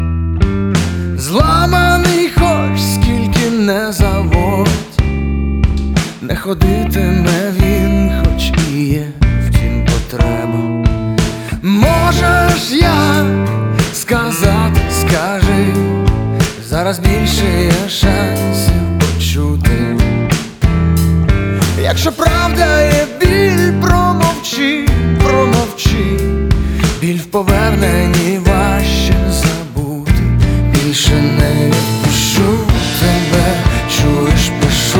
Жанр: Поп / Украинские